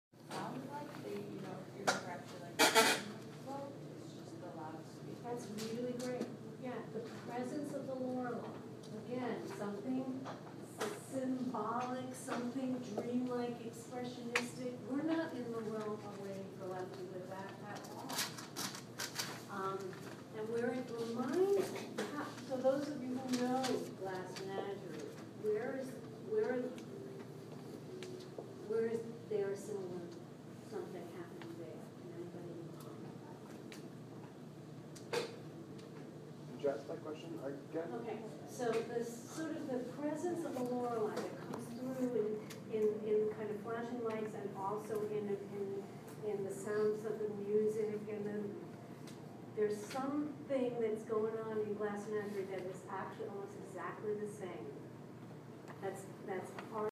Field Recording #1
Location: Dunkin’ on the Quad Sounds: Music, chattering, thuds and chairs moving, orders being called.
Classroom-Noises.mp3